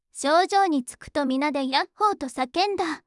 voicevox-voice-corpus
voicevox-voice-corpus / ita-corpus /もち子さん_怒り /EMOTION100_016.wav